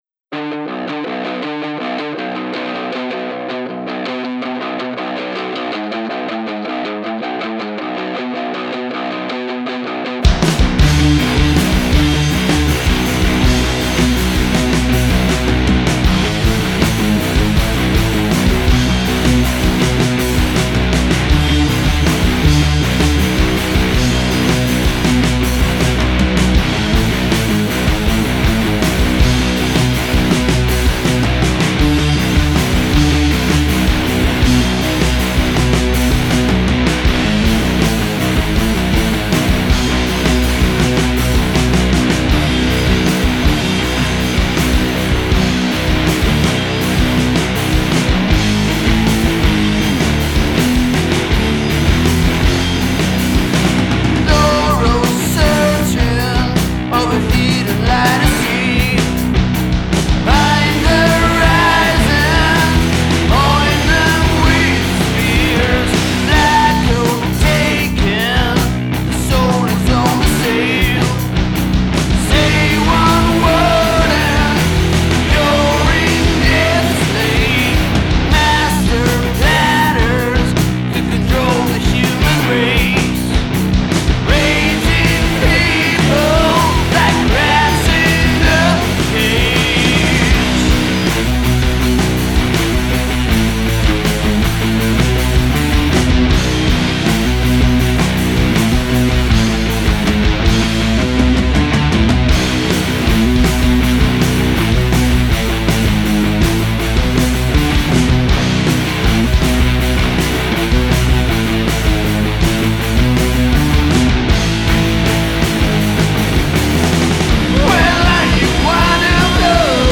Heavy rockers